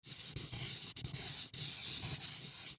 Звуки маркера
Шуршание маркера по бумаге